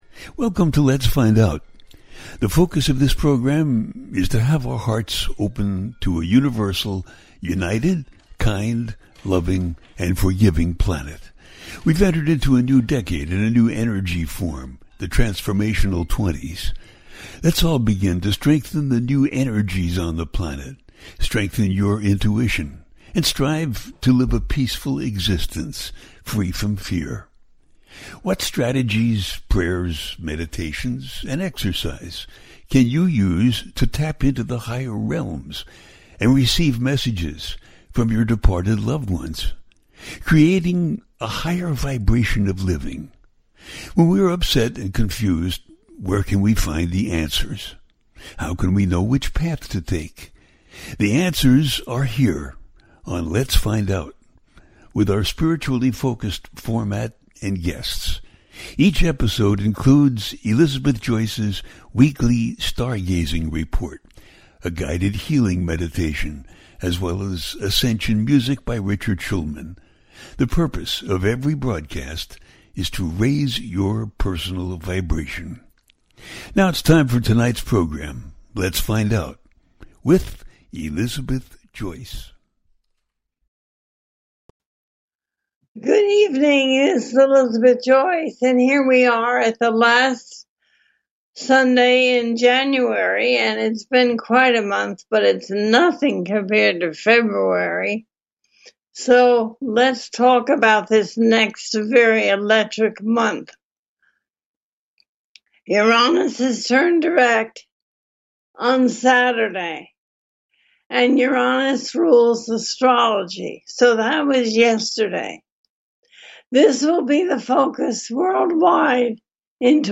Talk Show Episode, Audio Podcast, Lets Find Out and Uranus turns Direct-February Astrology and Predictions-2024, A teaching show.
Uranus turns Direct-February Astrology and Predictions-2024, A teaching show.